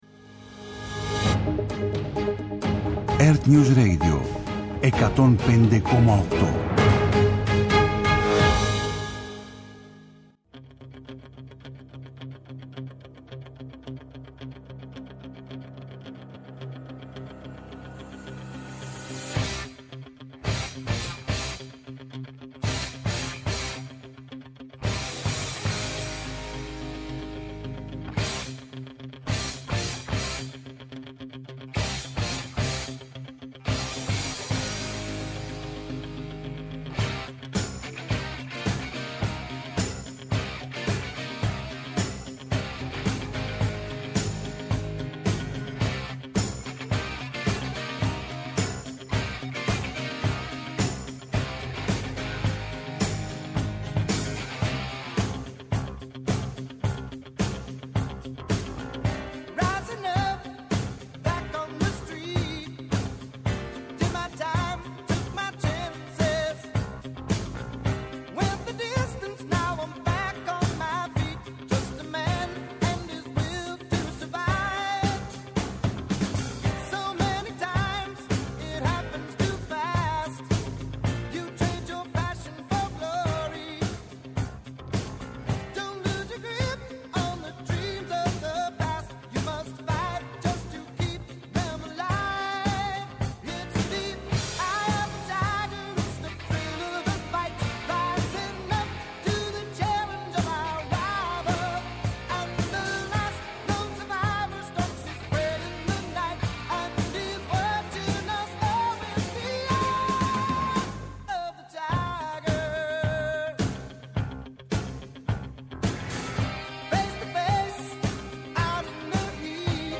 Απόψε μια συζήτηση για το παρόν και το μέλλον του ελληνικού ποδοσφαίρου, εκεί όπου οι νέοι παίκτες ονειρεύονται να γίνουν οι πρωταγωνιστές της επόμενης μέρας!